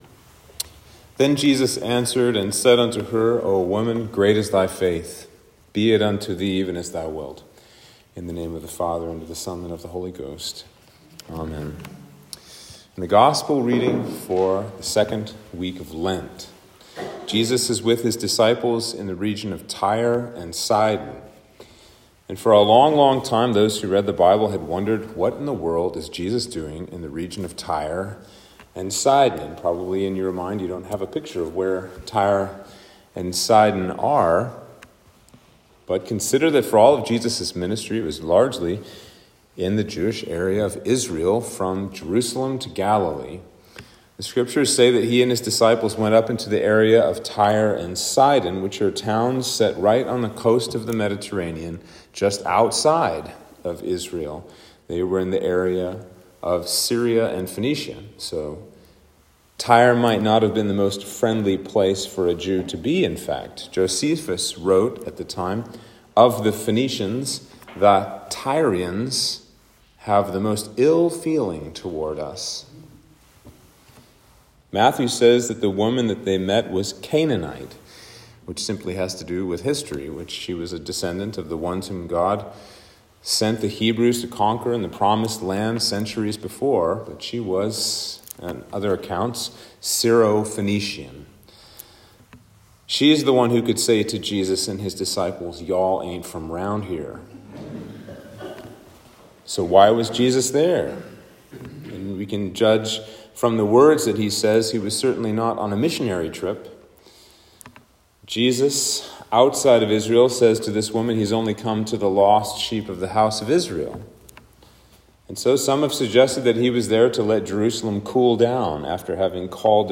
Sermon for Lent 2